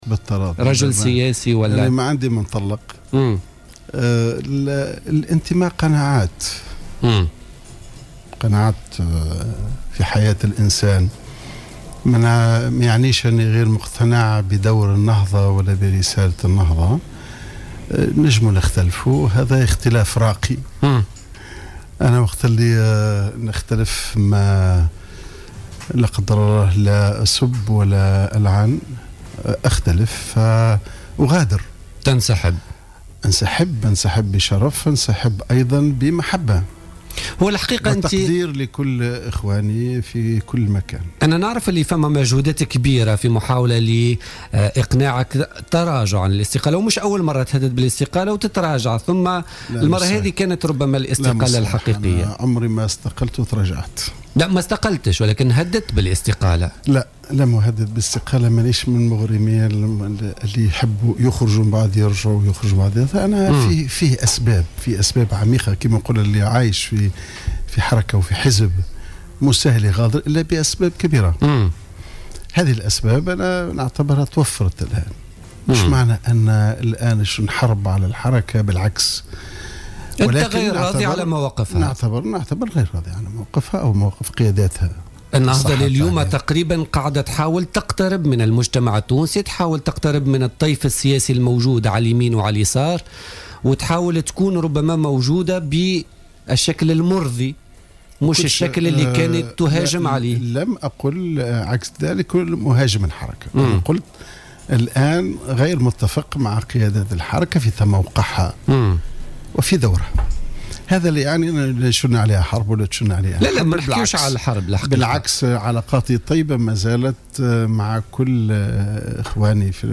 قال الأمين العام السابق لحركة النهضة حمادي الجبالي ضيف برنامج "بوليتيكا" اليوم إن استقالته من الحركة كانت لأسباب عميقة ولاختلافه مع بعض القيادات على دور الحركة وتموقعها الآن في الساحة السياسية،مشيرا إلى أن عودته إلى الحركة مستحيلة.